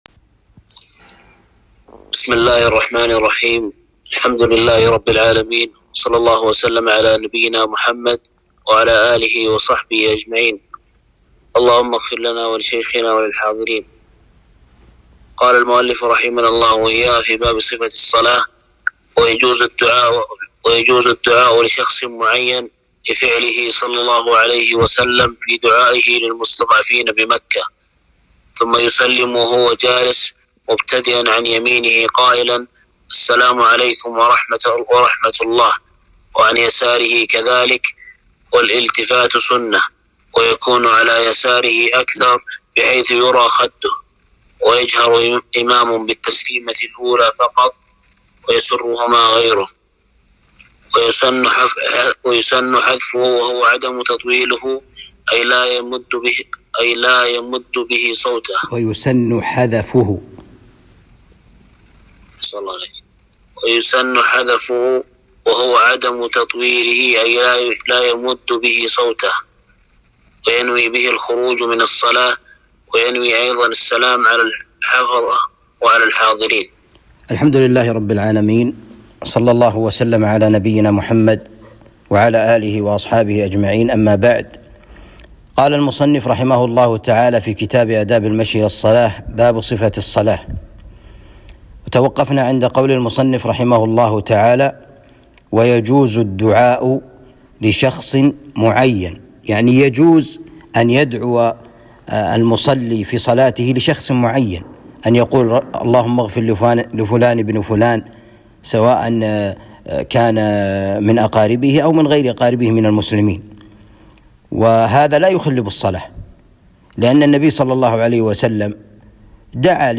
الدروس شرح كتاب آداب المشي إلى الصلاة